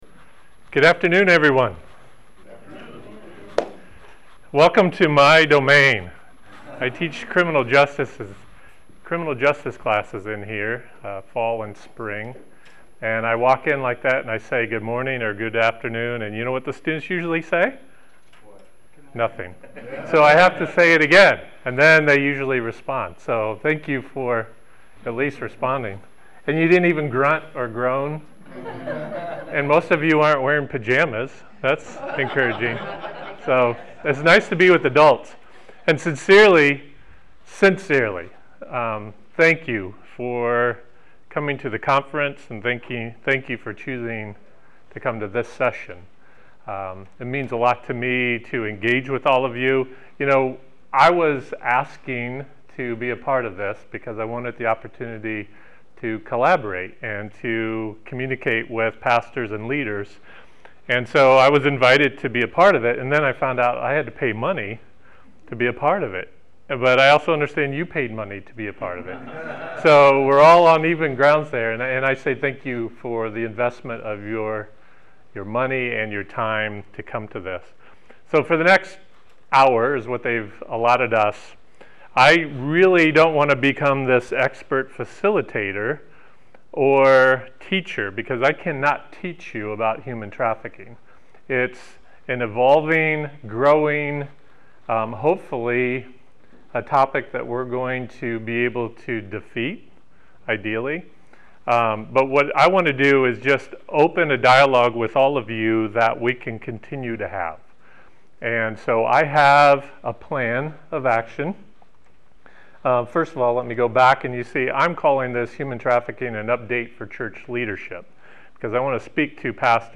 The Church has identified the abolitionist movement as a new ministry area both internationally and at home. Gain insight through this workshop and identify practical resources for your church to provide leadership in your community.